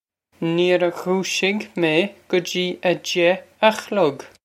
Pronunciation for how to say
Neer goo-shig guh gee a jeh a klug.
This is an approximate phonetic pronunciation of the phrase.